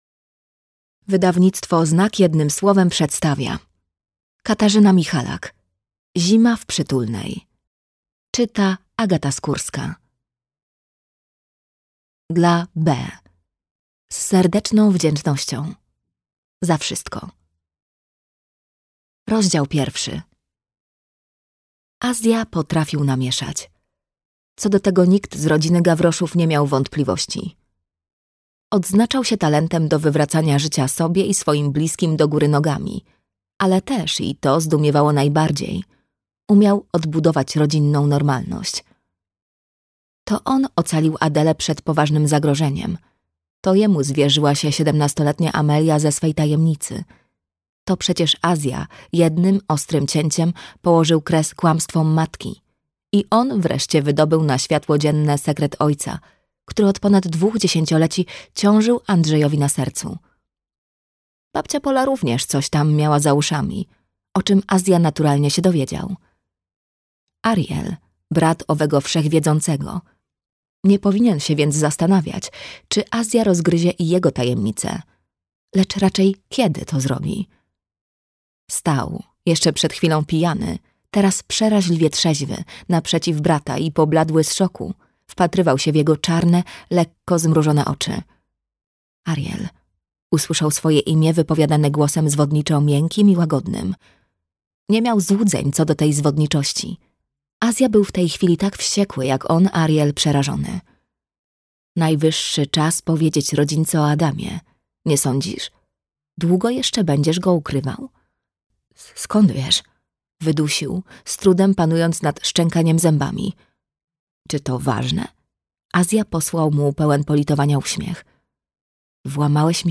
Zima w Przytulnej - Katarzyna Michalak - audiobook